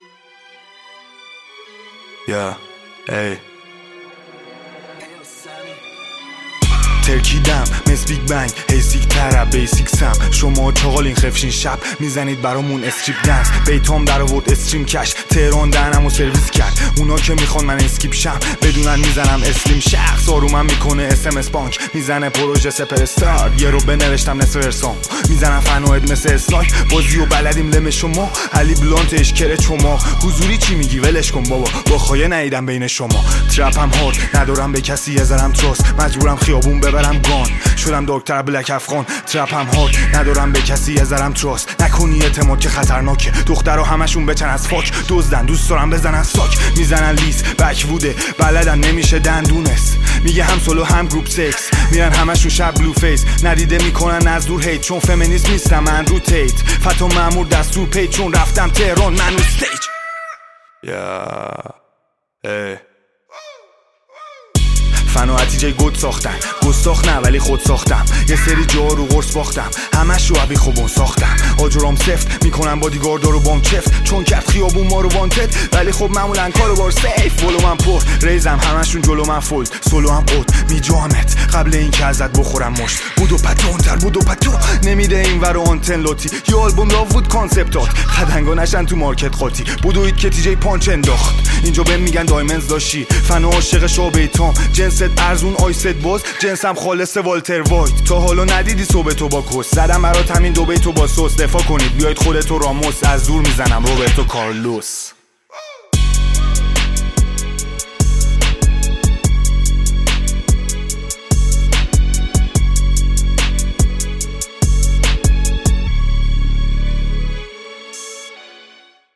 آهنگ رپ